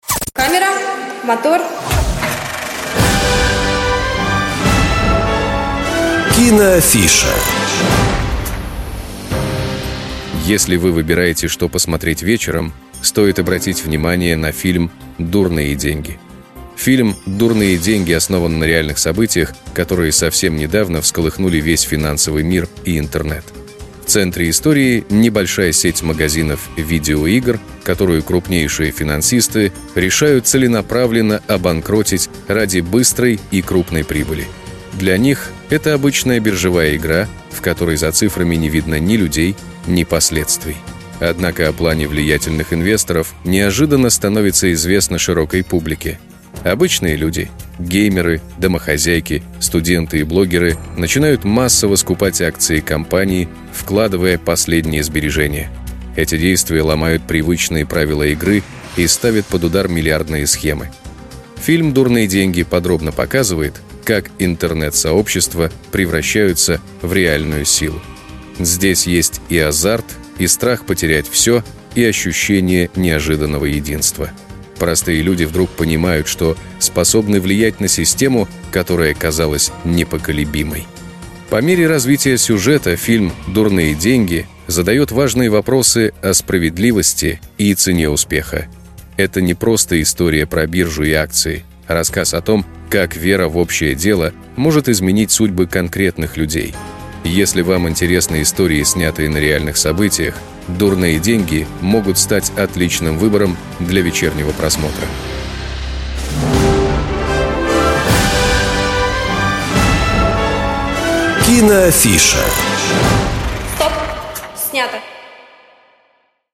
Небольшие аудиорассказы о фильмах и сериалах, которые помогут определиться с выбором на вечер.